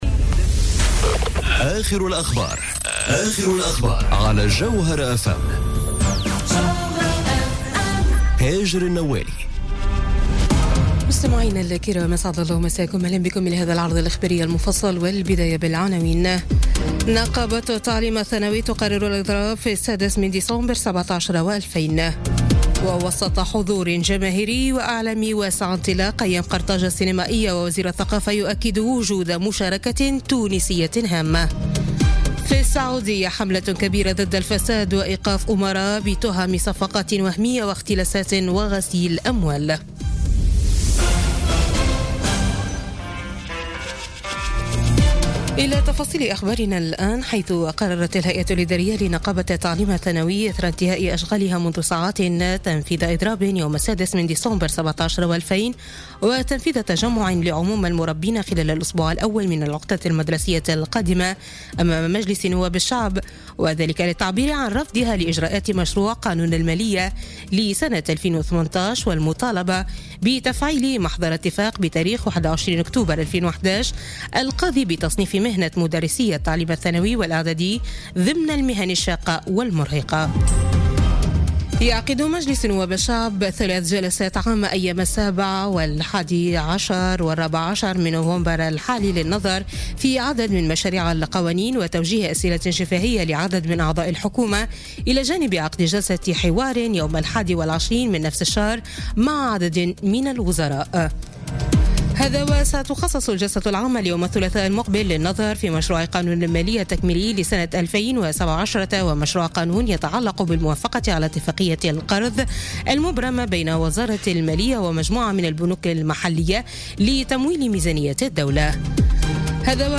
نشرة أخبار منتصف الليل ليوم الاحد 05 نوفمبر 2017